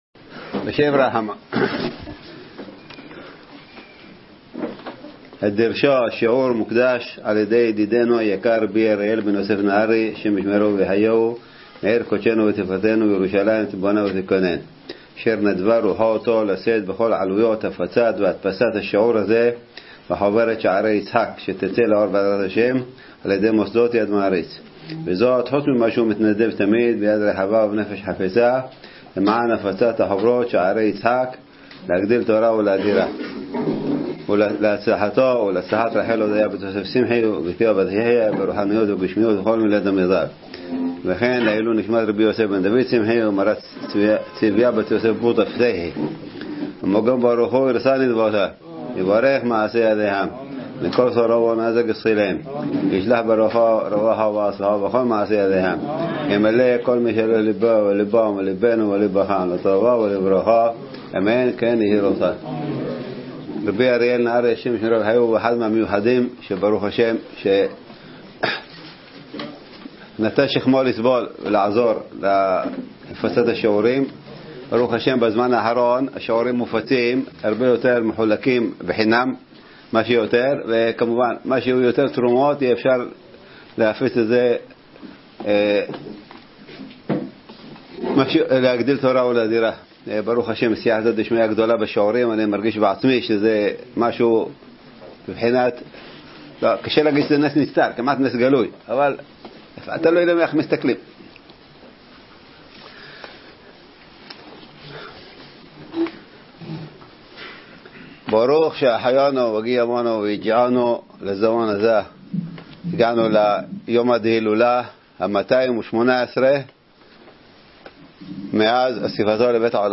דרשת מרן שליט"א שנמסרה במסגרת הילולת מהרי"ץ ה-218 - התשפ"ג